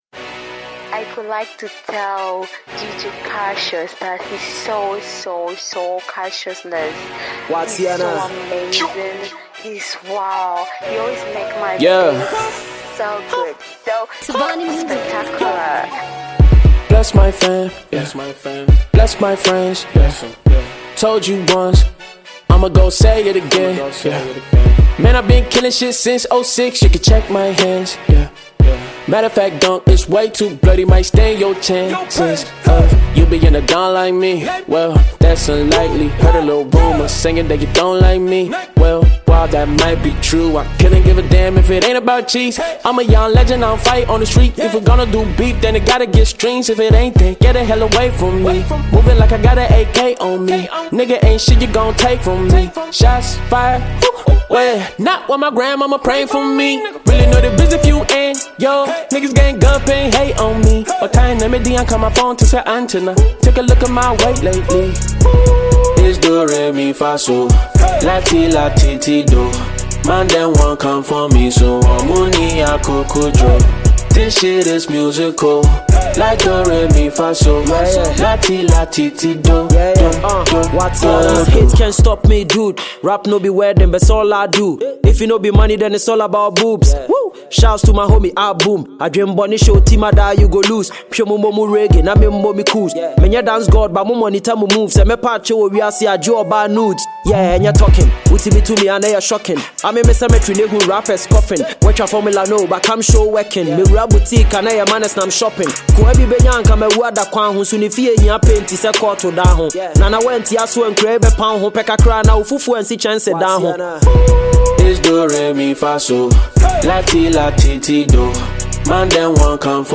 hardcore rap single